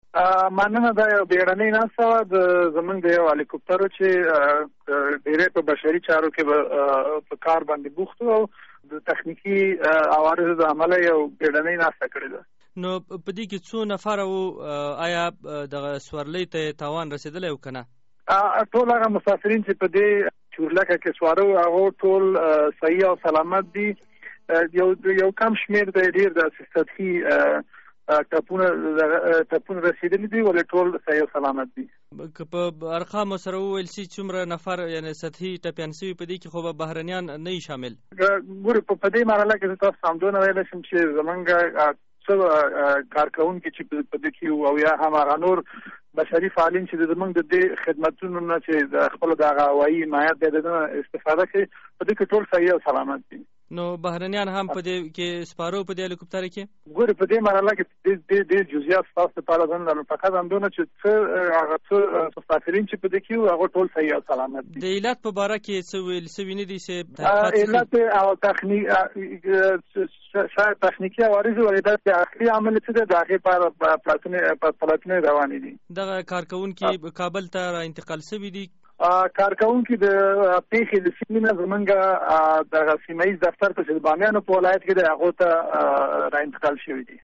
له نظیف الله سالارزي سره مرکه